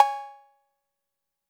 Index of /m8-backup/M8/Samples/Drums/Hits/TR808/CB